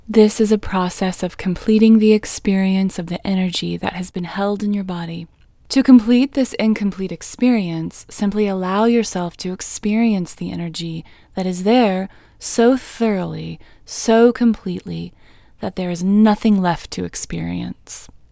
IN Technique First Way – Female English 17